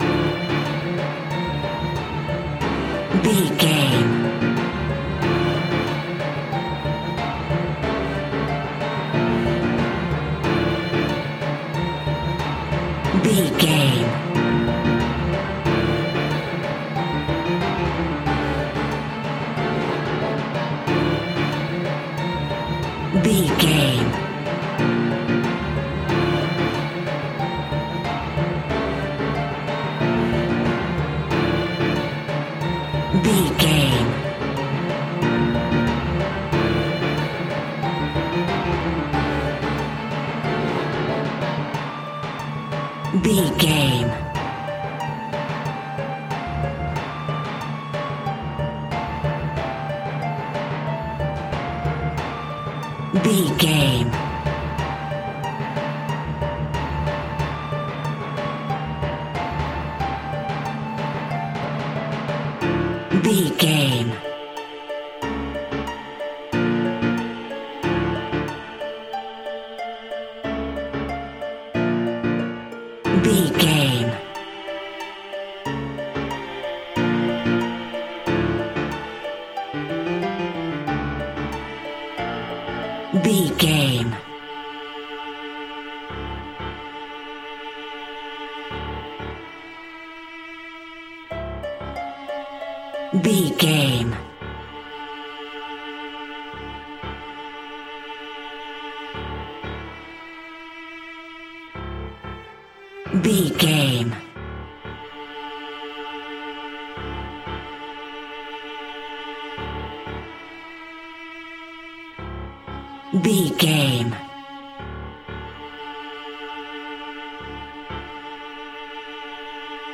Aeolian/Minor
D
Fast
scary
tension
ominous
dark
suspense
eerie
strings
piano
drums
percussion
synthesiser
horror
ambience
pads